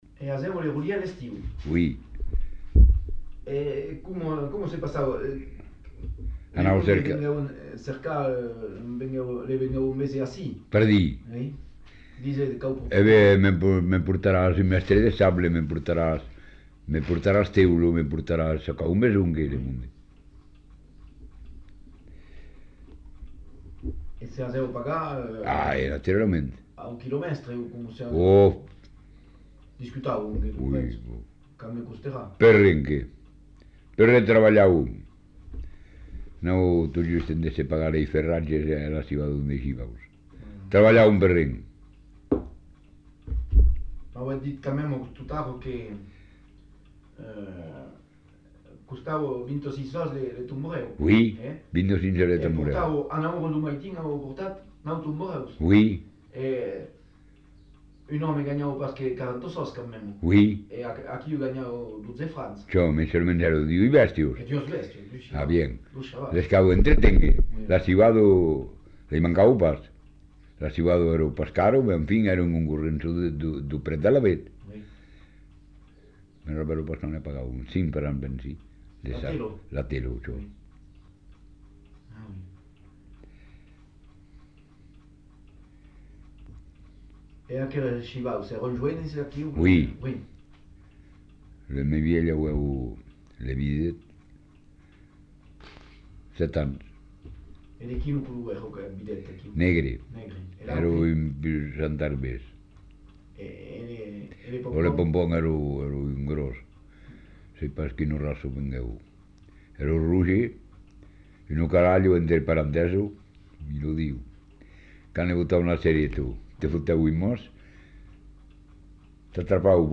Lieu : Saint-Thomas
Genre : témoignage thématique